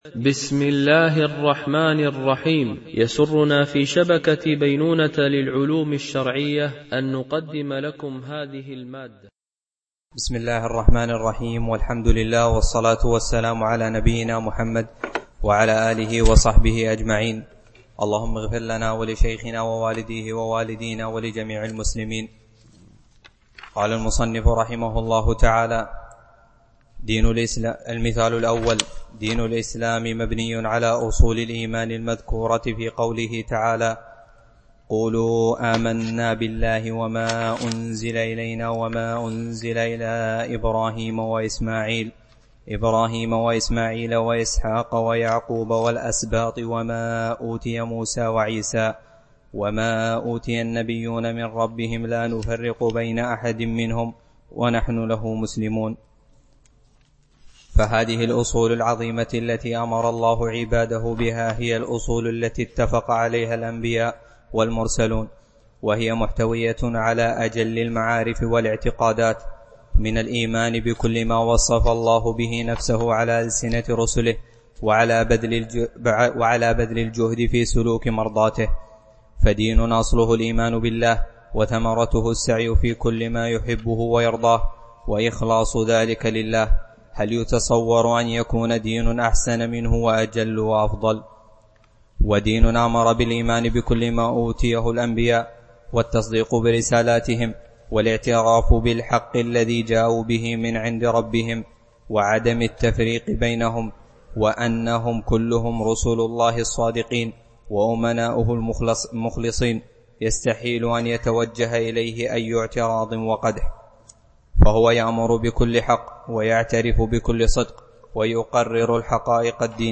دورة علمية شرعية: لمجموعة من المشايخ الفضلاء، بمسجد عائشة أم المؤمنين - دبي (القوز 4)